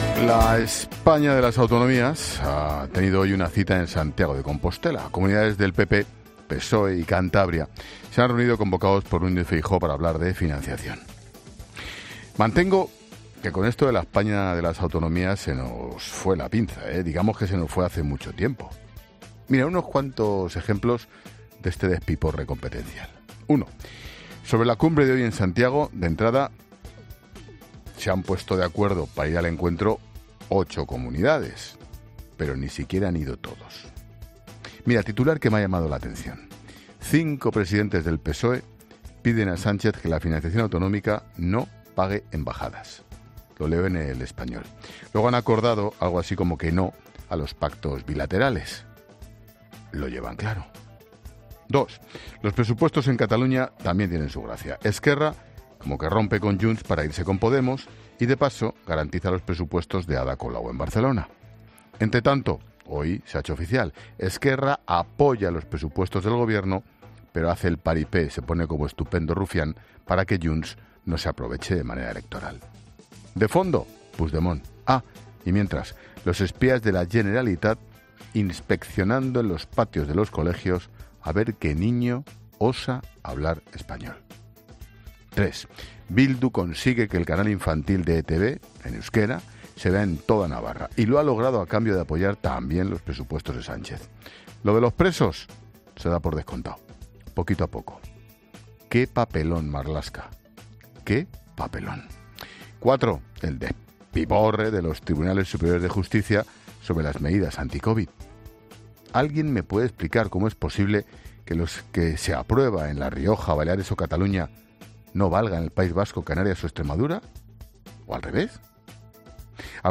Monólogo de Expósito
El director de 'La Linterna', Ángel Expósito, desglosa en su monólogo las principales claves de las noticias del día